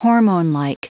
Prononciation du mot hormonelike en anglais (fichier audio)